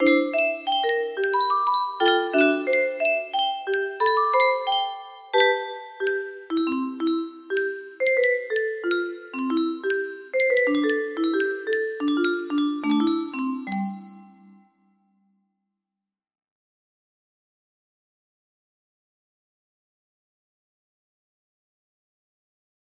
Unknown Artist - Untitled music box melody